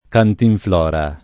cantinflora [ kantinfl 0 ra ]